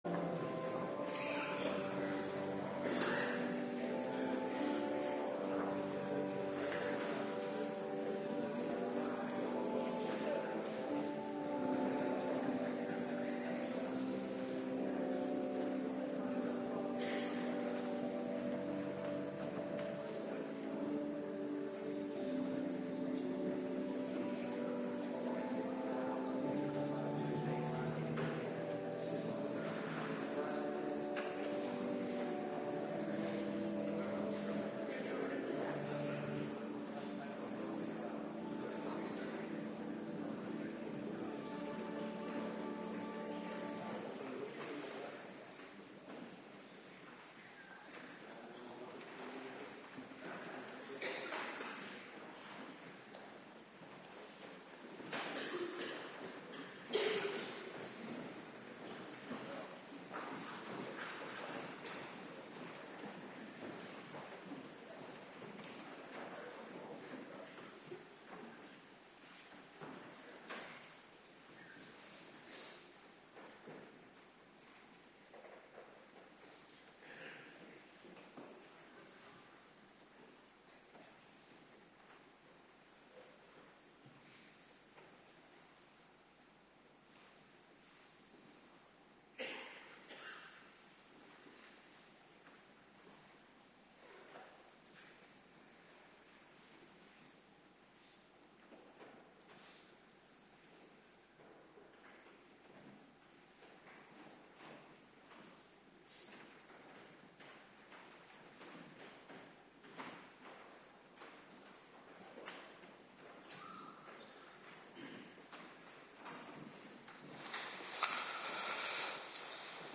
Avonddienst
Locatie: Hervormde Gemeente Waarder